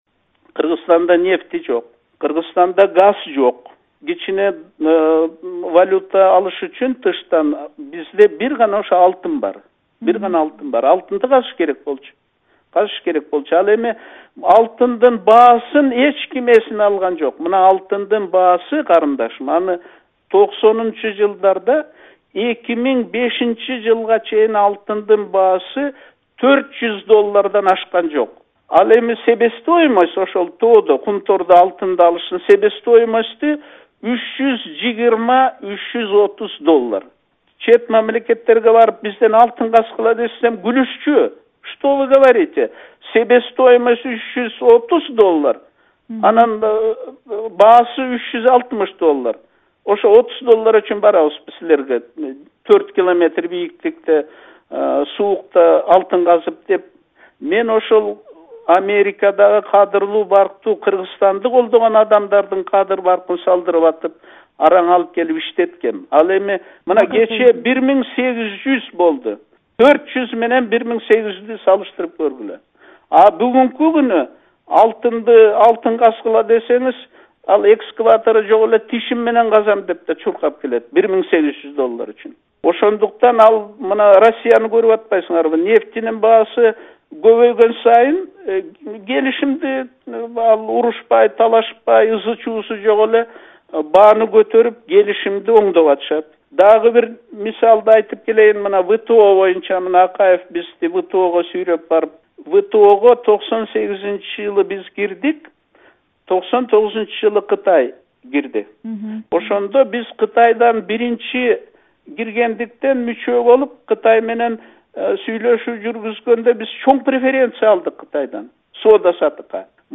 Аскар Акаев менен маек (3-бөлүк)